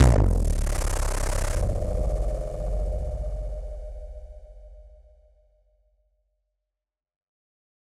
BF_SynthBomb_A-01.wav